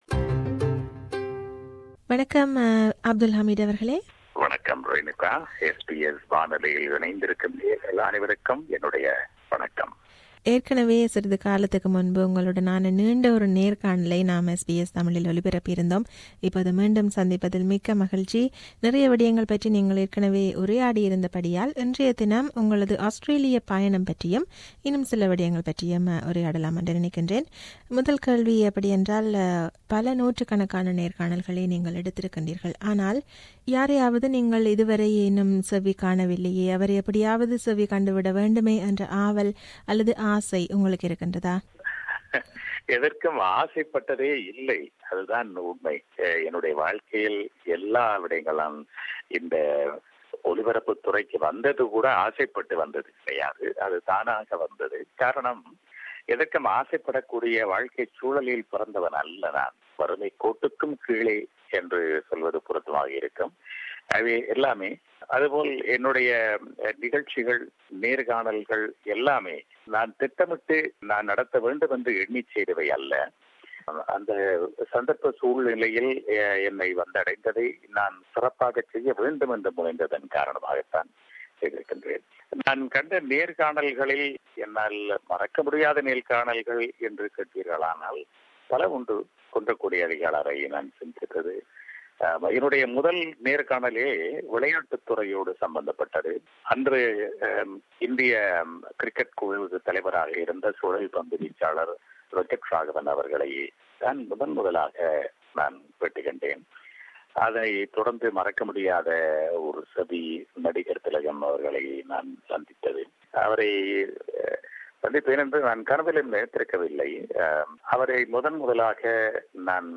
An interview with B.H.Abdul Hameed
He is coming to Australia to host Mahajana Malai 2017 and Ponmalai Poluthu 2017. This is an interview with him.